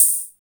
TR-55 OPEN0I.wav